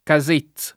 [ ka @%Z ]